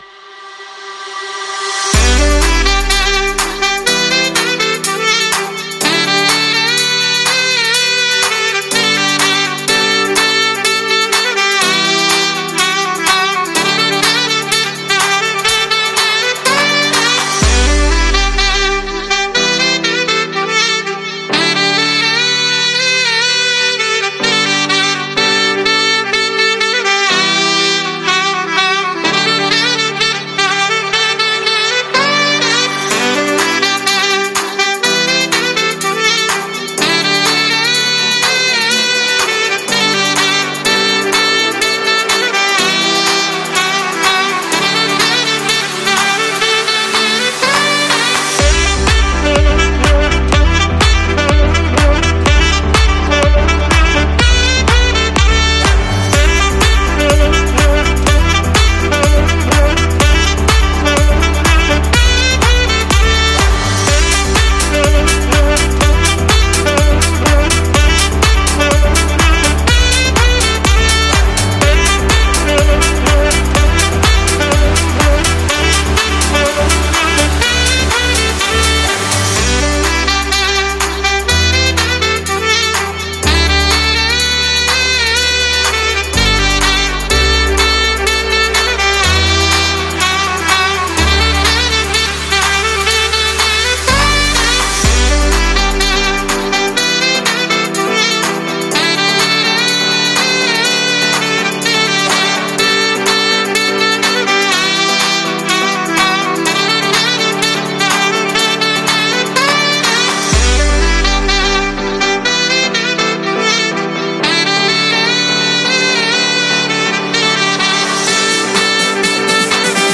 پلی لیست بهترین آهنگ های ساکسوفون (بی کلام)
Saxophone